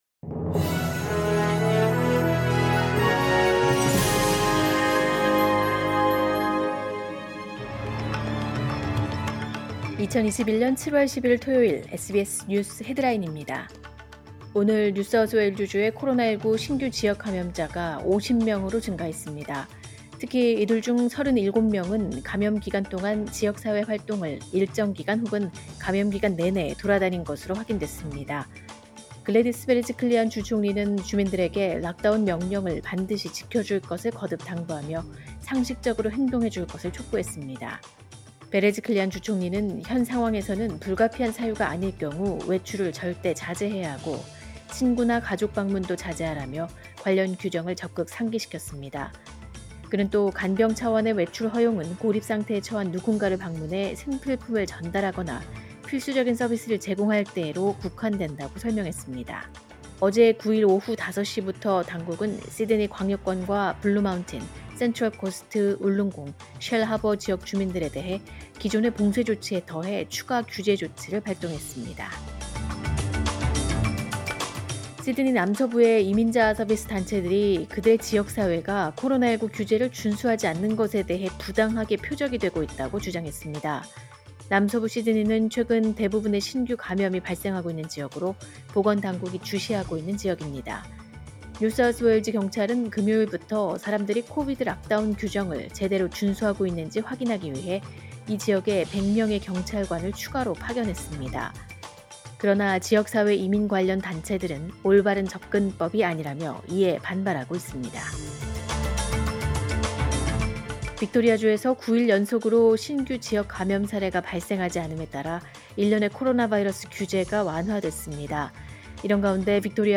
2021년 7월 10일 토요일 SBS 뉴스 헤드라인입니다.